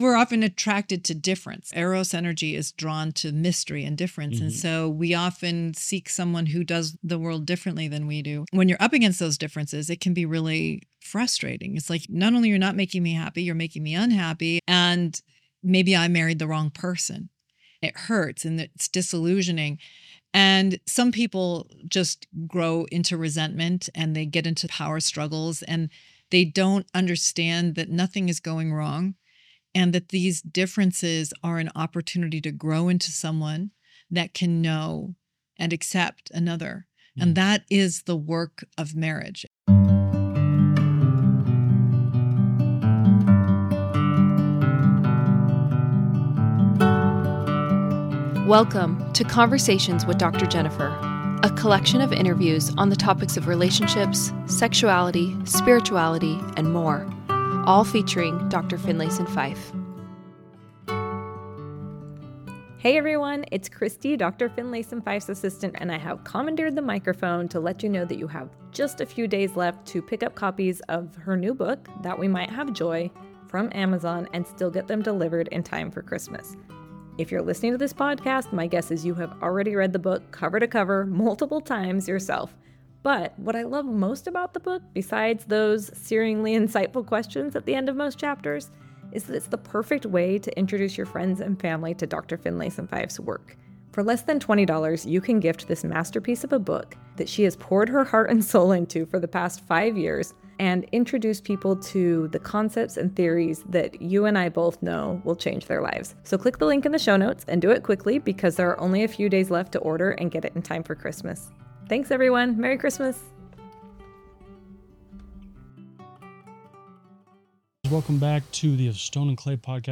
A conversation about intimacy and desire